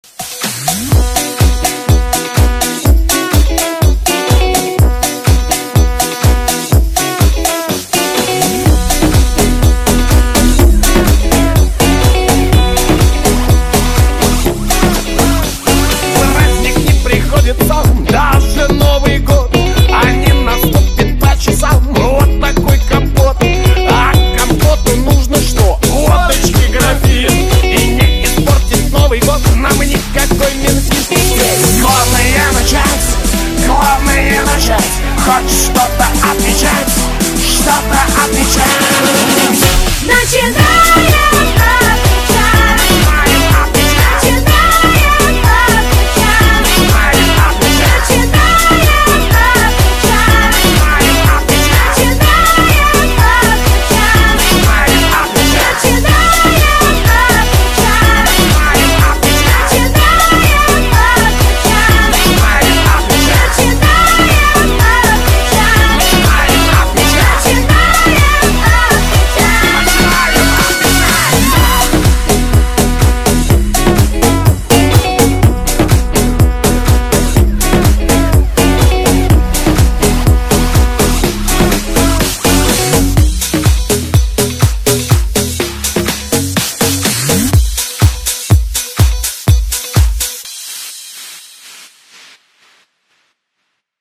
• Качество: 320, Stereo
мужской вокал
женский вокал
веселые